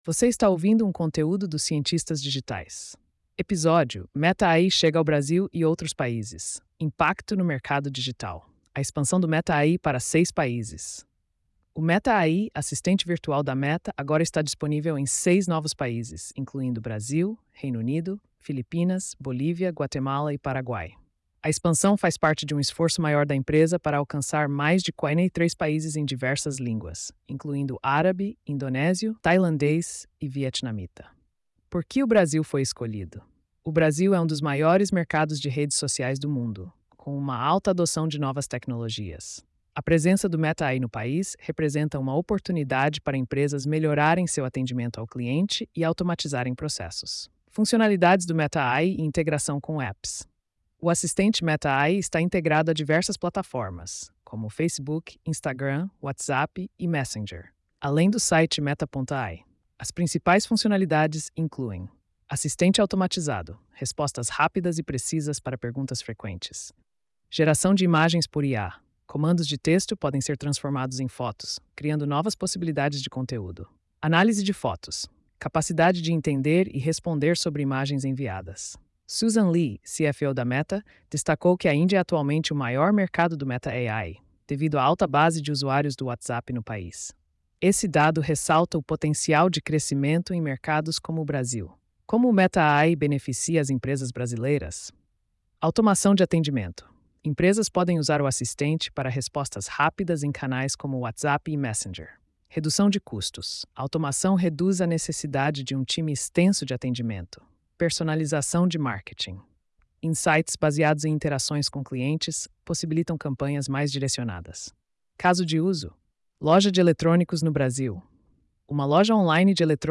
post-2553-tts.mp3